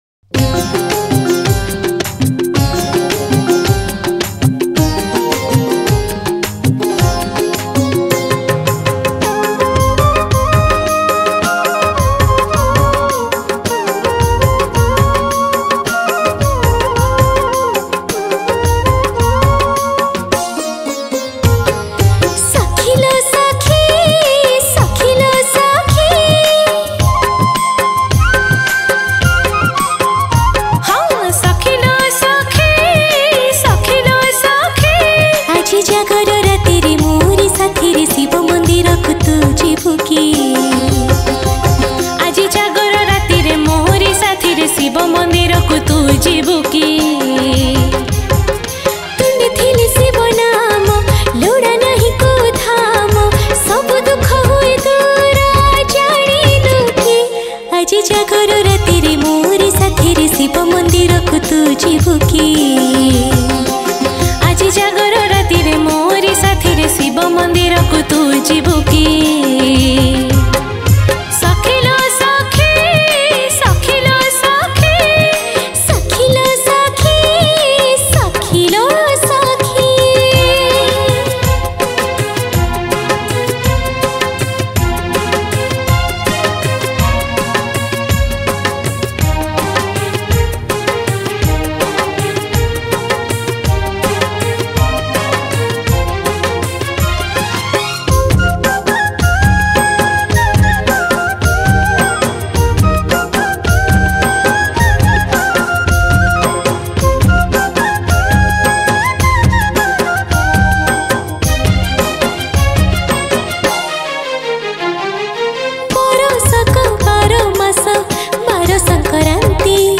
Jagara Special Odia Bhajan Song Upto 2021 Songs Download